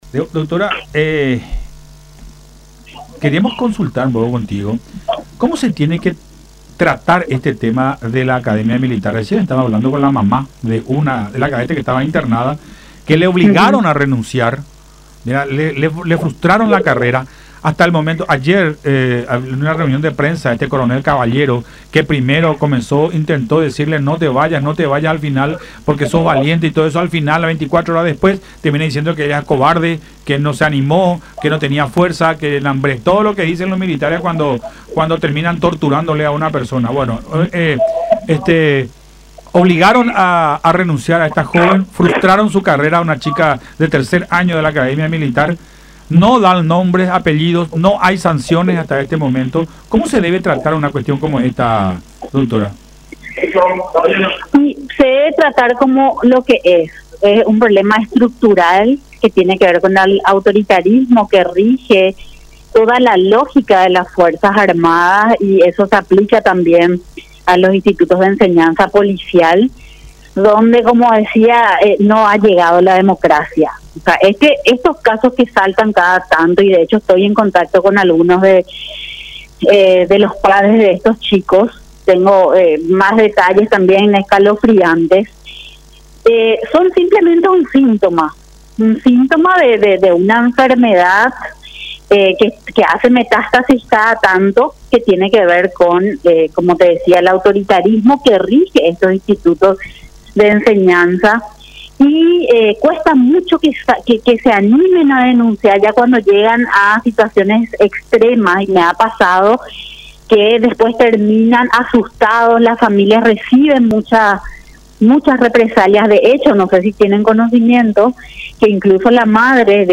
en diálogo con Buenas Tardes La Unión